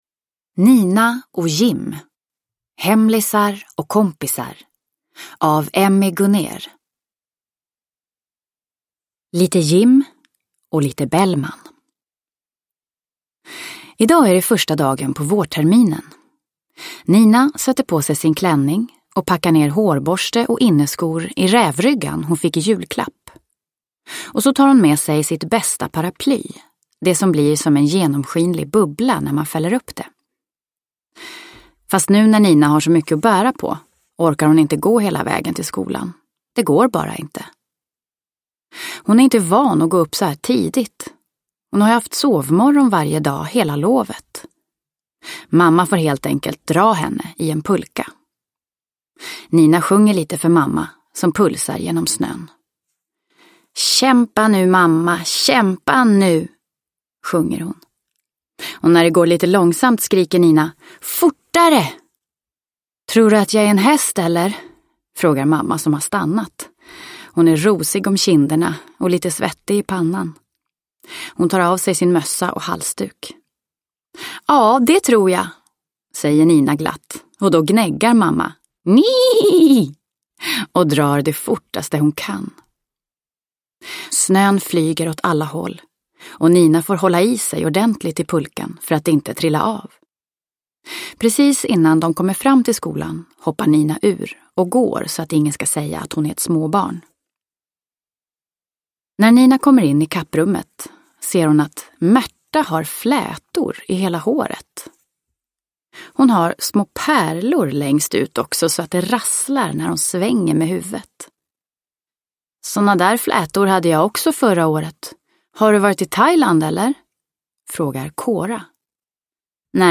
Nina & Jim. Hemlisar & kompisar – Ljudbok